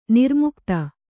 ♪ nirmukta